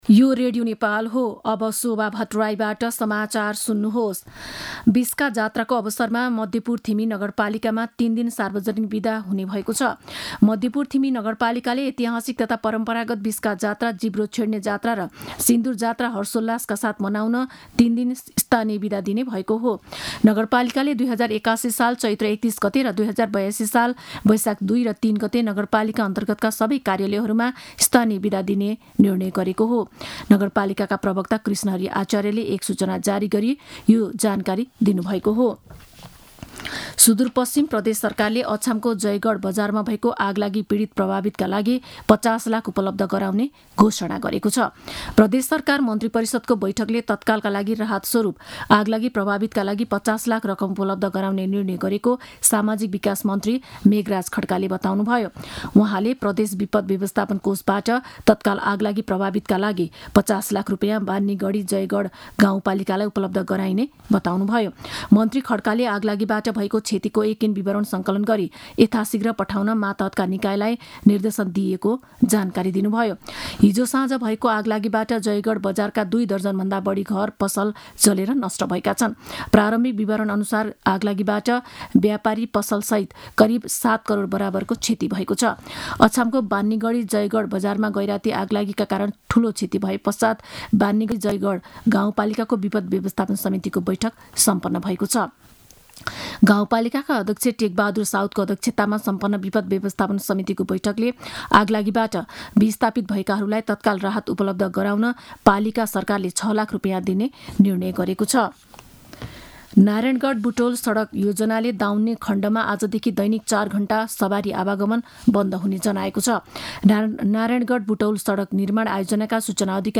दिउँसो १ बजेको नेपाली समाचार : २७ चैत , २०८१
1-pm-news.mp3